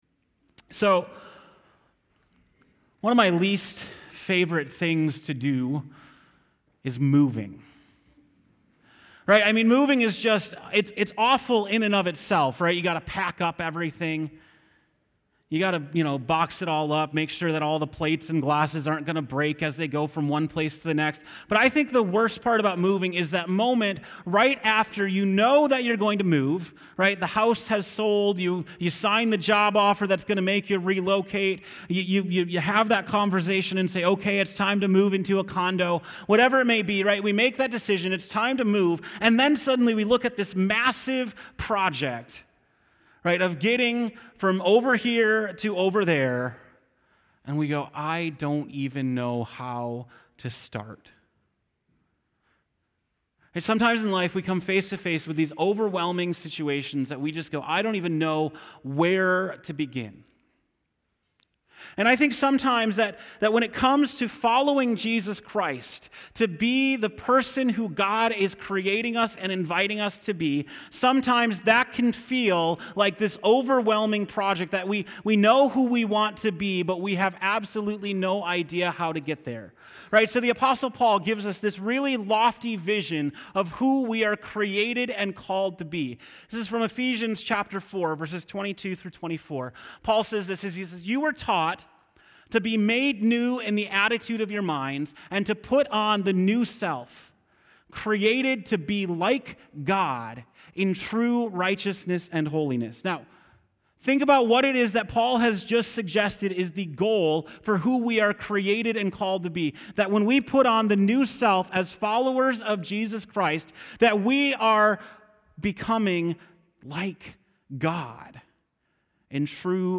October 14, 2018 (Morning Worship)